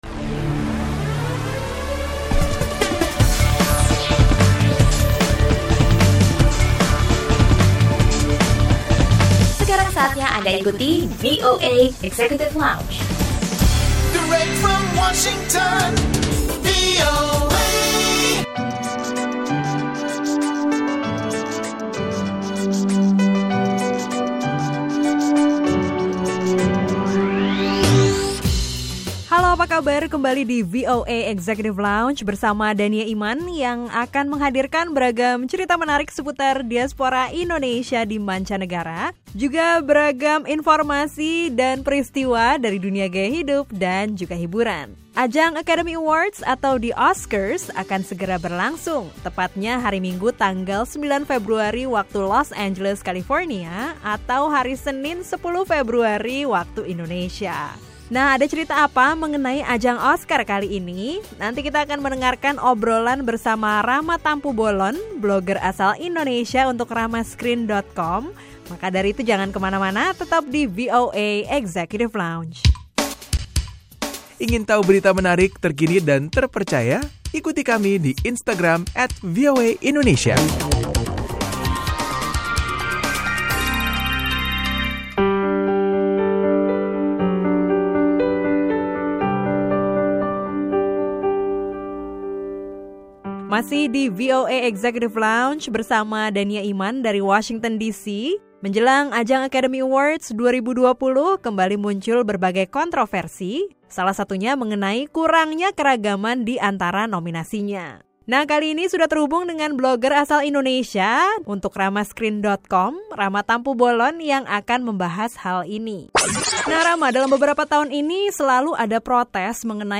Laporan wartawan